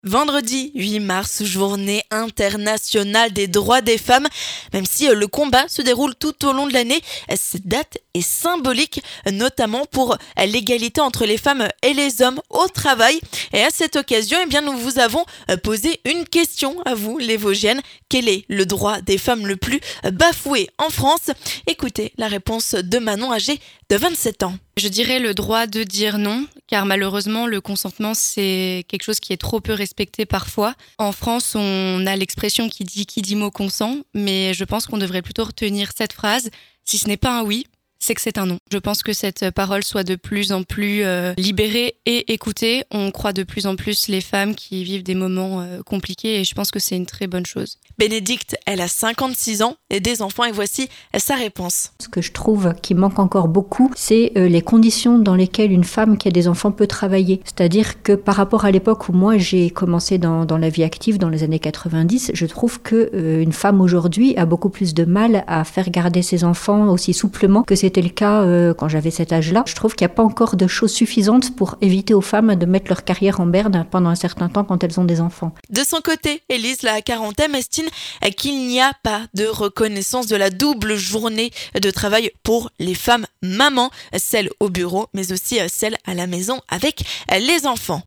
Nous avons décidé de vous donner la parole ! Et de vous demander quel était, selon vous, le droit des femmes le plus bafoué en France.